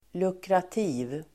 Uttal: [lukrat'i:v el. l'uk:-]